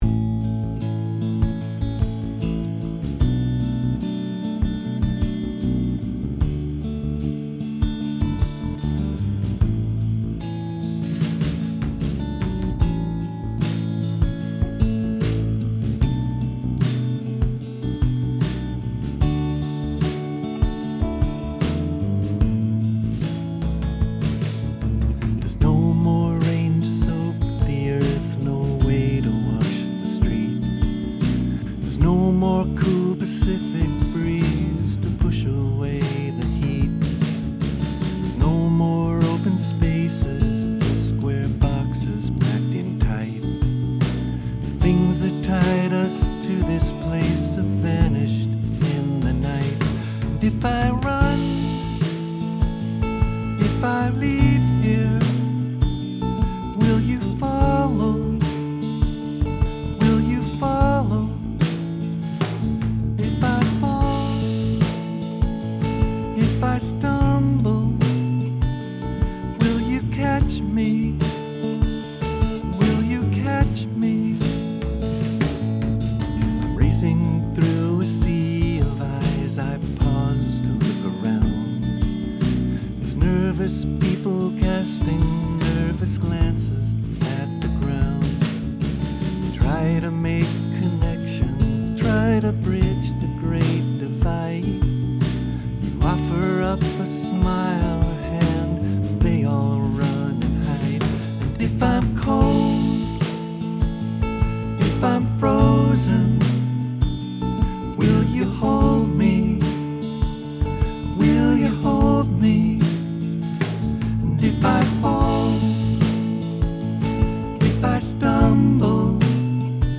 This is my anthem song, best played in front of no fewer than 20,000 fans.